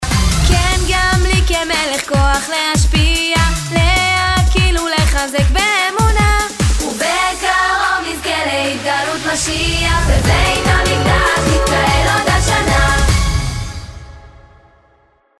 צלצול בנות גרסה 2
צלצול-בנות-2.mp3